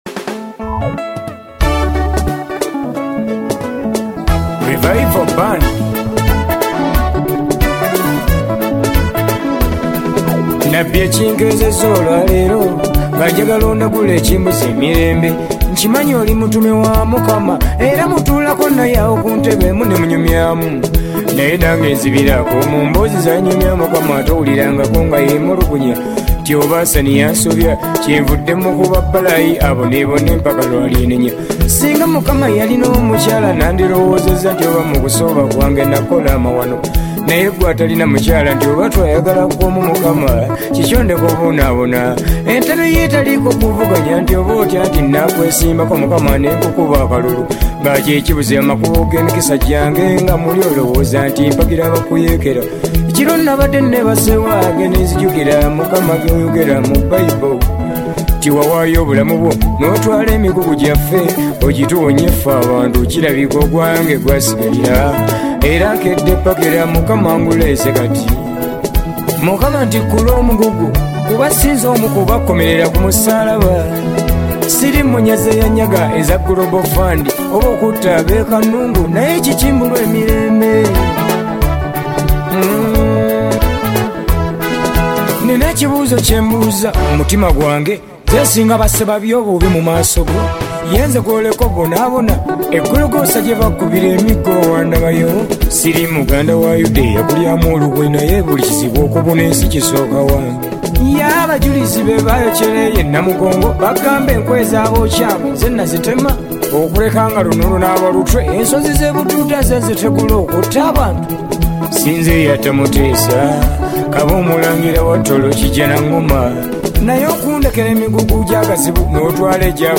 Kadongo Kamu